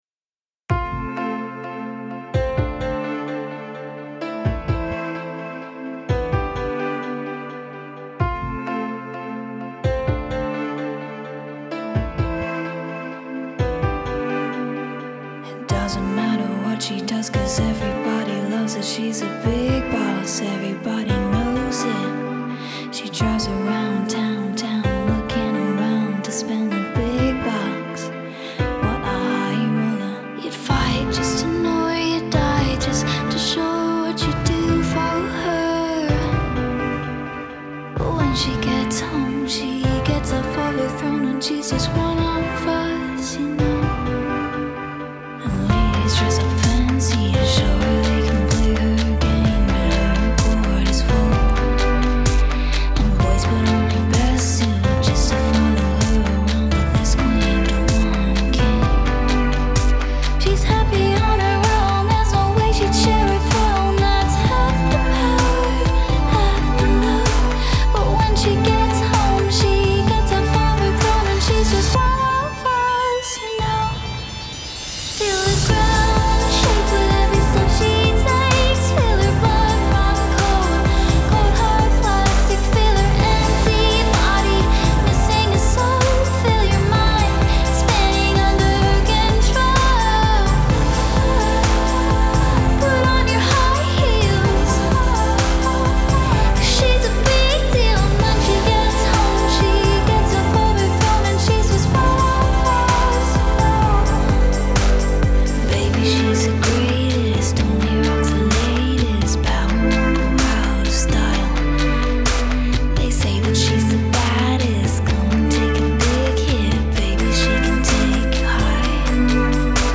Genre: Electronic, Pop